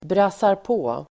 Ladda ner uttalet
Uttal: [brasarp'å:]